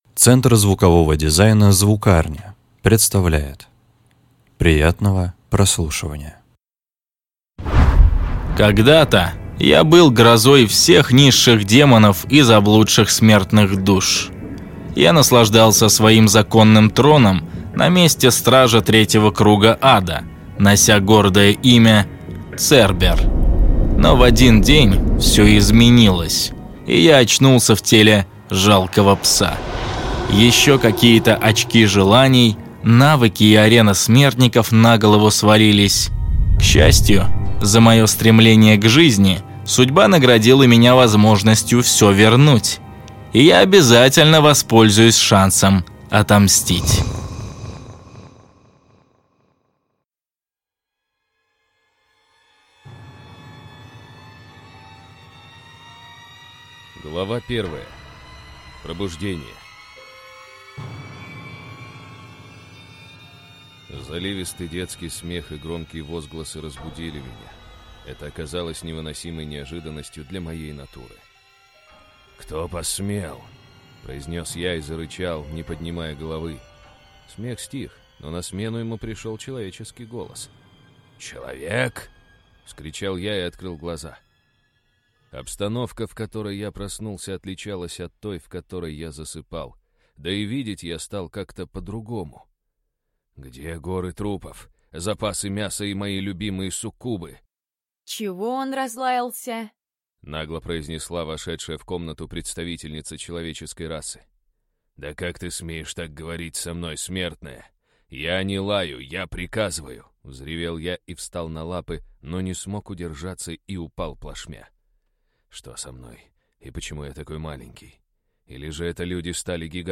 Аудиокнига Цербер. Перерождение | Библиотека аудиокниг
Перерождение Автор Влад Андреевич Туманов Читает аудиокнигу Студия Voicerecord.